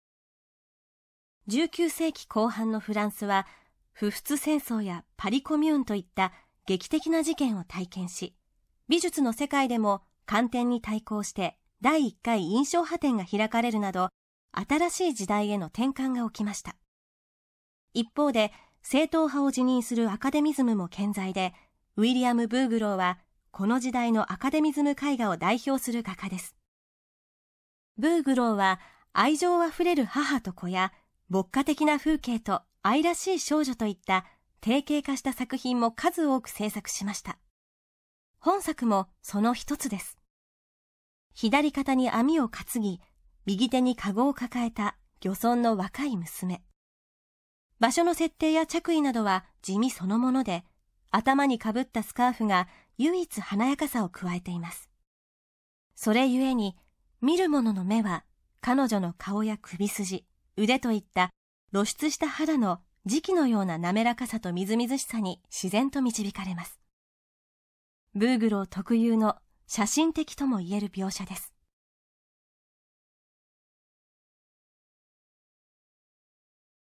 作品詳細の音声ガイドは、すべて東京富士美術館の公式ナビゲーターである、本名陽子さんに勤めていただいております。